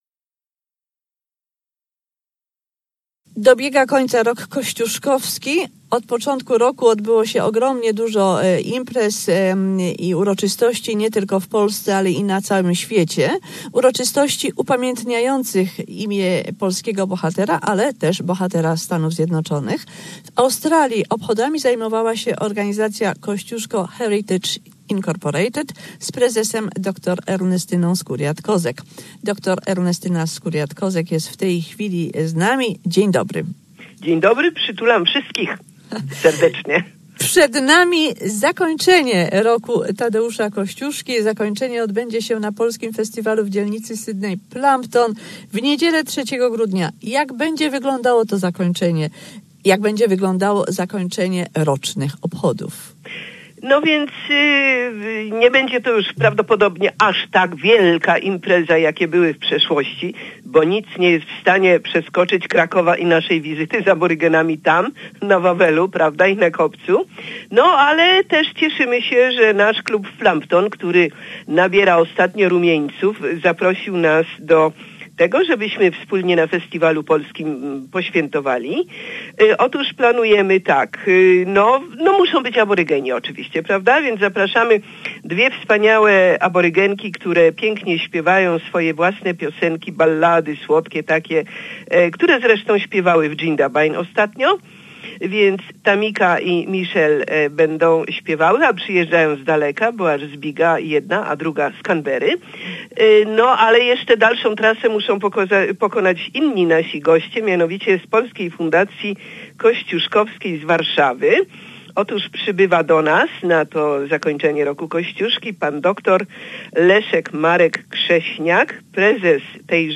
Wywiad na naszym serwerze [Zobacz]